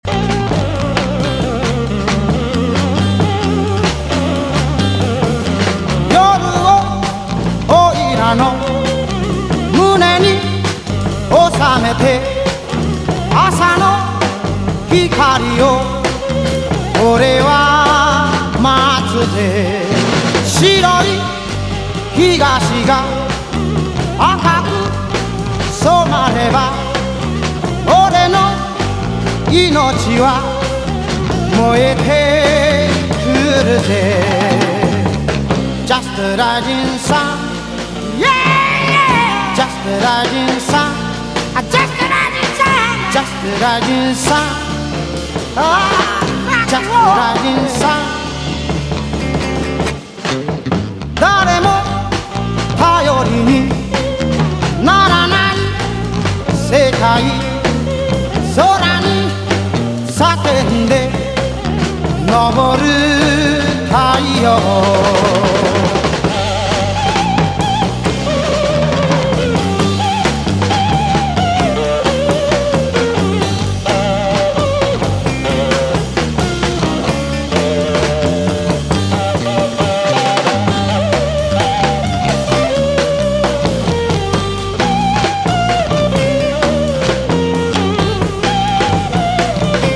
ROCK / 60'S / PSYCHEDELIC ROCK / GARAGE ROCK / FREAK BEAT